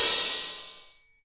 VSCO 1打击乐库 鼓 " 小鼓（小鼓3 rimshot ff 1
标签： 边敲击 打击乐器 小鼓 VSCO-2 单票据 多重采样
声道立体声